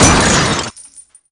SHATTER.WAV